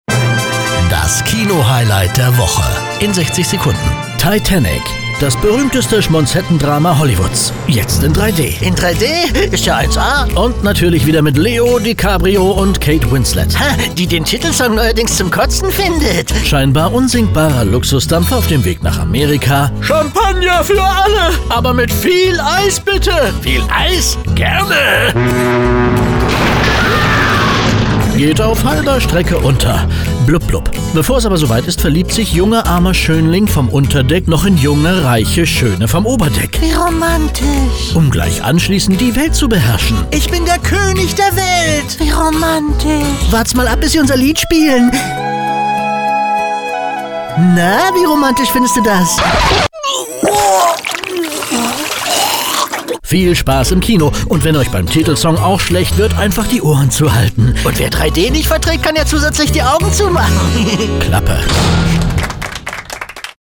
Radiocomedy - Sprecher mit Homestudio aus Leipzig, Hörbuchsprecher, Werbesprecher, Synchronsprecher | e-learning, Voice Over, Funkspots, Synchron, TV, Doku, Kommentar, Imagefilm